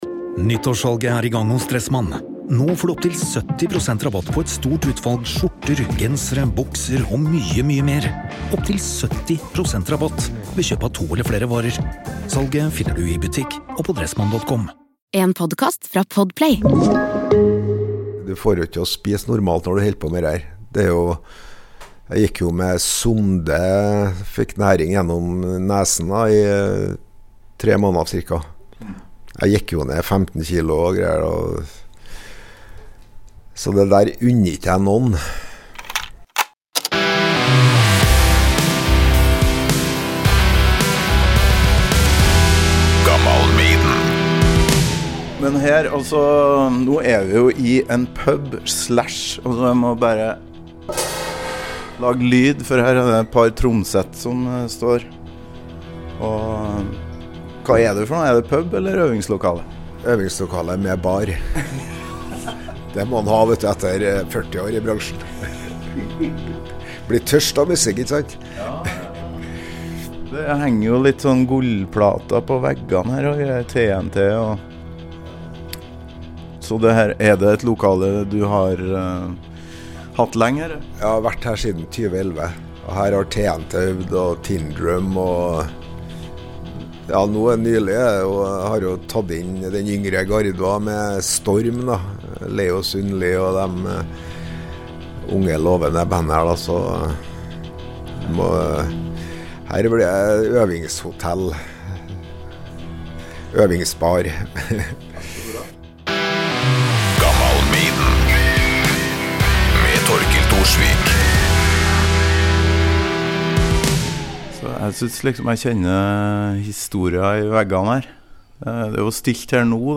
Gammal Maiden tar turen til øvingslokalet "Kvarteret" ved Dora i Trondheim for en prat om gamleda'r og det som har skjedd det siste halvåret. Hvordan har det gått med den folkekjære musikeren?